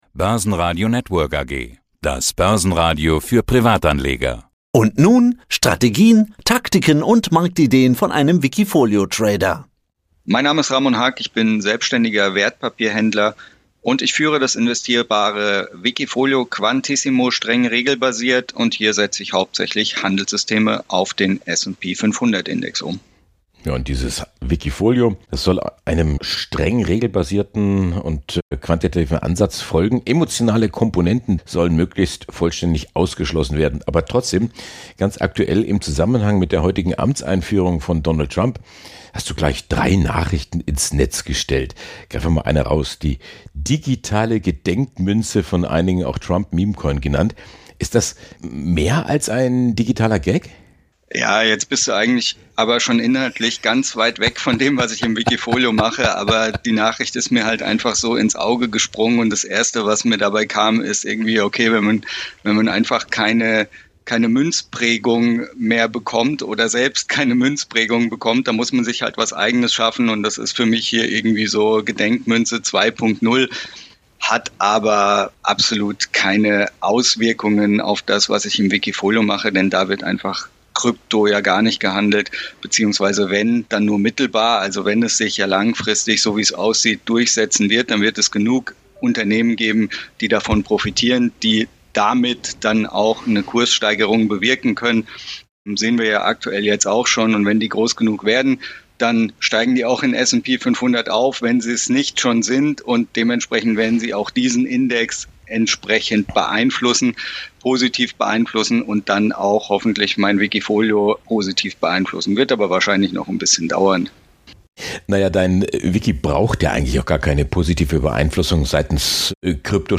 Ein optimistischer Blick auf 2025 und die Herausforderungen starker Marktschwankungen runden das Gespräch ab.